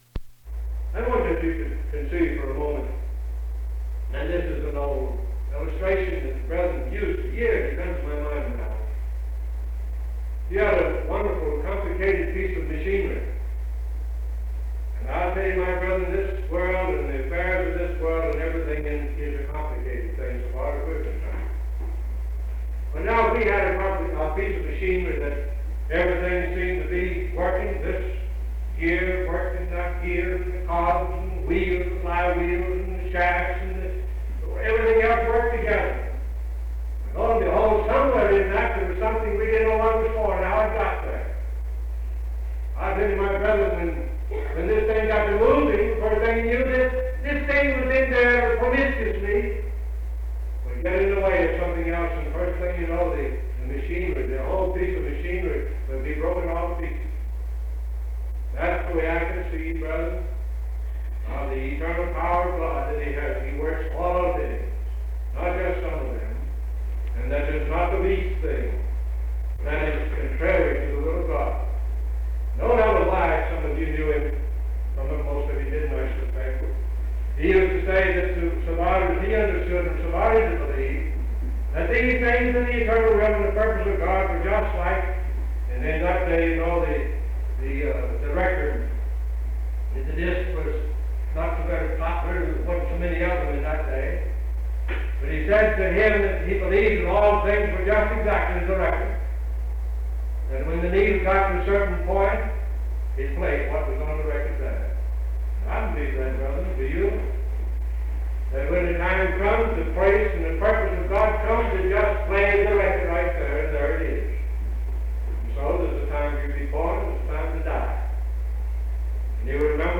Sermons
at an all-day meeting at Frying Pan Church
Primitive Baptists